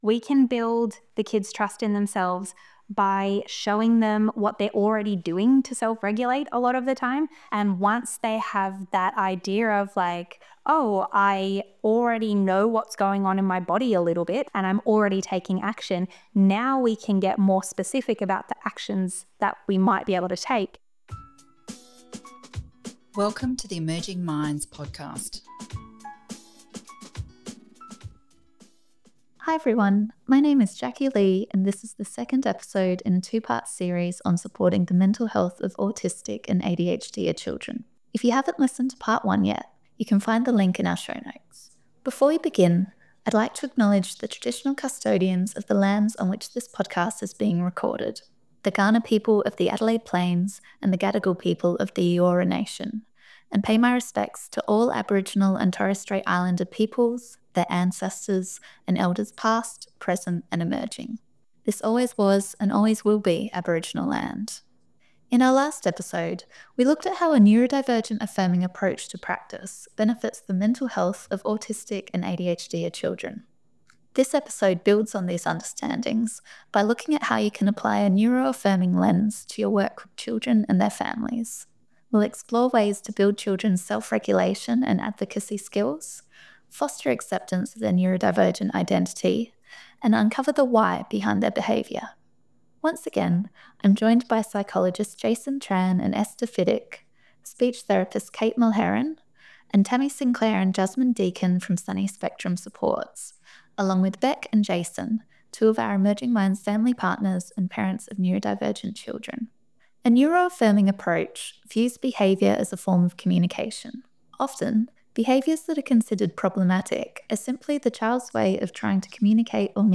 In this episode, practitioners and parents of autistic and ADHDer children explore ways to build children’s self-regulation and advocacy skills, foster acceptance of their neurodivergent identity, and uncover the ‘why’ behind their behaviour.